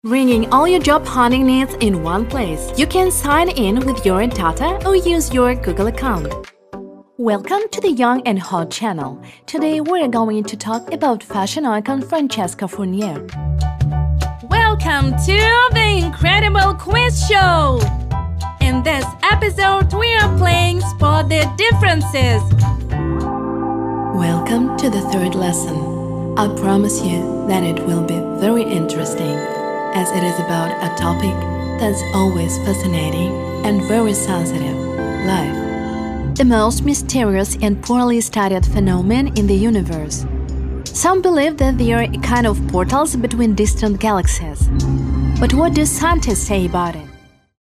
Пример звучания голоса
Микс_отлично
Жен, Рекламный ролик/Средний
Работаю на профессиональном оборудовании (домашняя и профессиональная студии), работаю с опытным звукорежиссером.